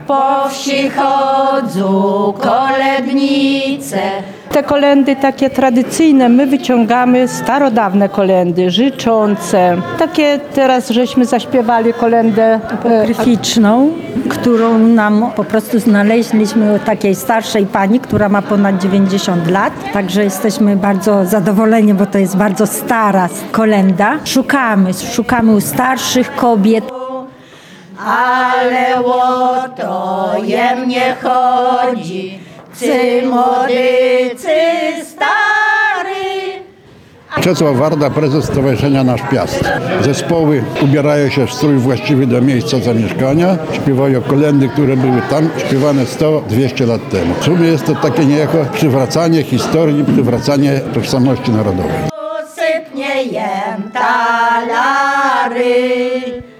Tradycyjne kolędy na scenie w Lublinie.
Zespół „Marianki” z gminy Dzwola jedną z takich pieśni zagrał specjalnie dla Słuchaczy Radia Lublin. – Wyciągamy tradycyjne kolędy, życzące – opowiada członkini zespołu.